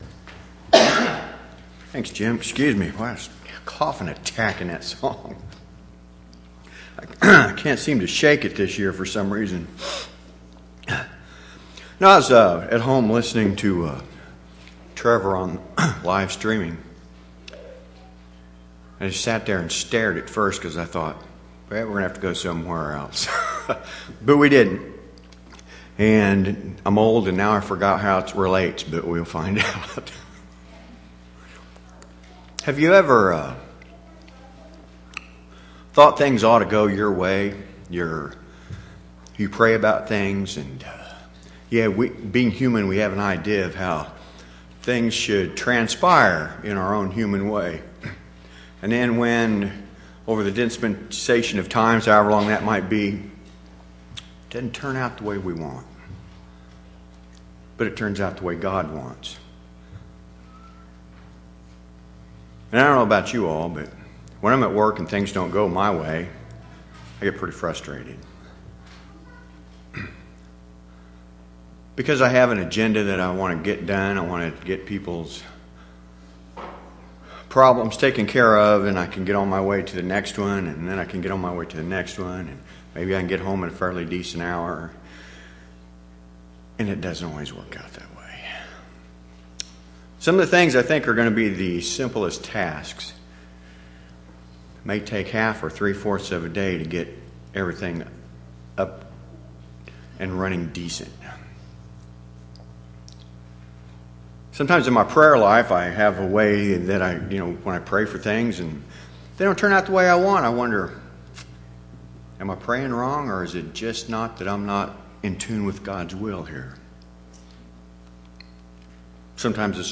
3/20/2016 Location: Temple Lot Local Event